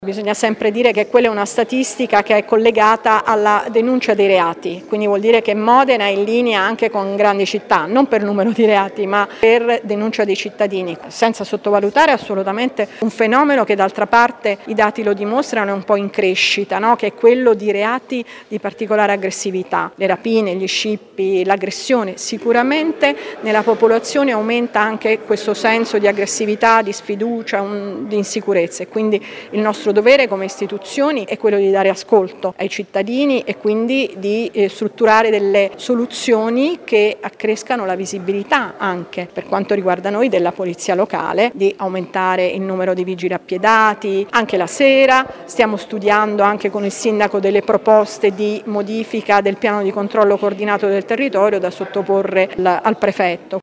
Cosa sta facendo il comune per garantire maggiore sicurezza ai cittadini? L’assessore alla sicurezza Alessandra Camporota commenta la classifica.